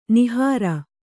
♪ nihāra